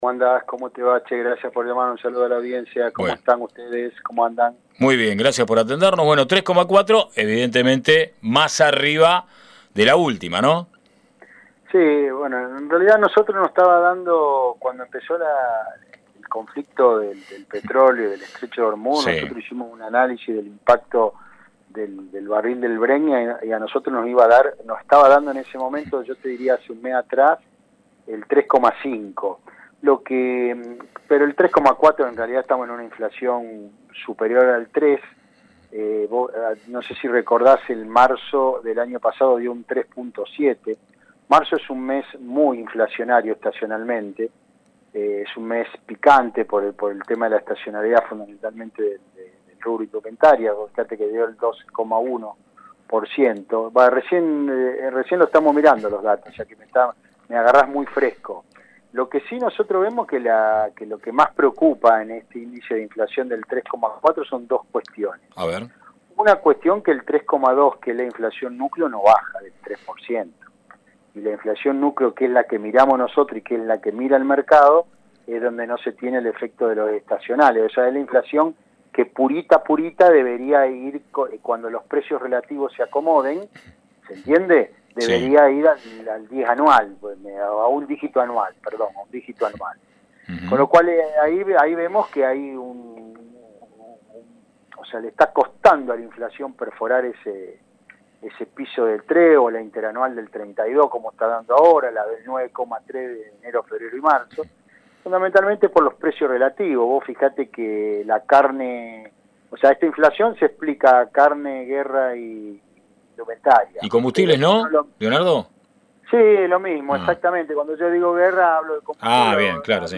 Nota en “A rio revuelto” – LU6 Radio Atlántica – Mar del Plata